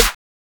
Snare.wav